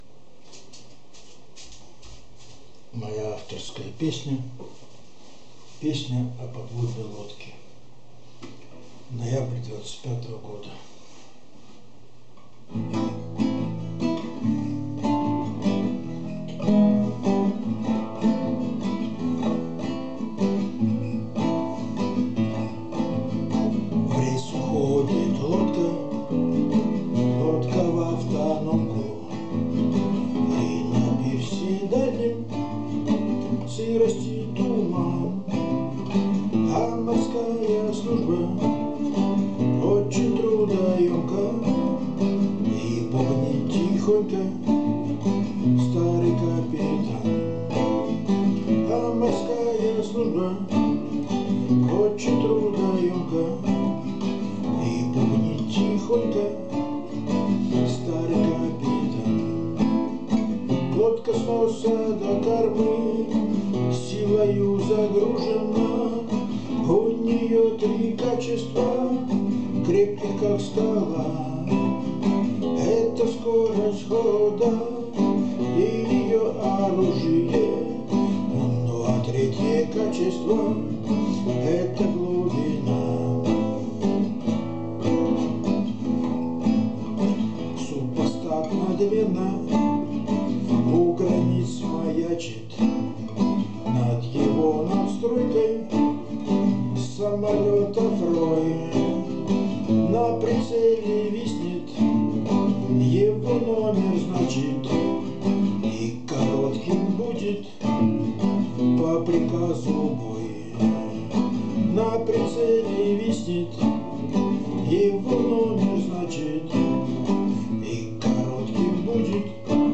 Жанр: песни о море